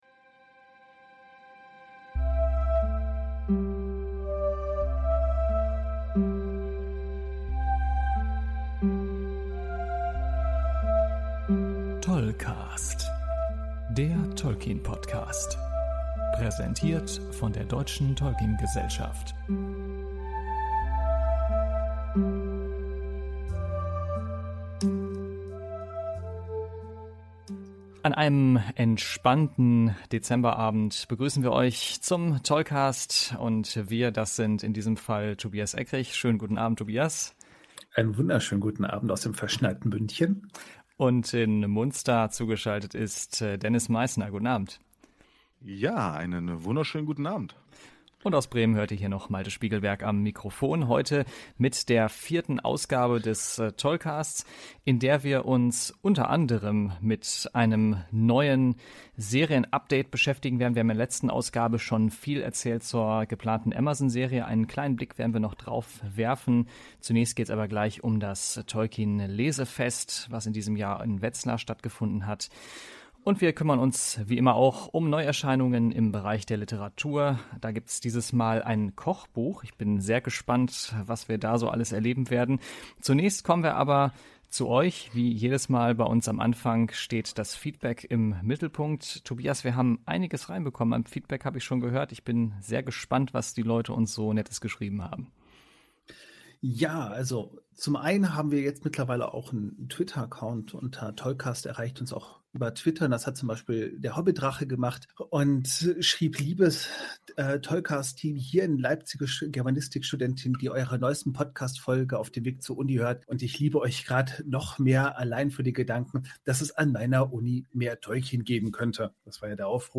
Dafür wieder mit Open Mic und der einen oder anderen Überraschung für die Moderatoren.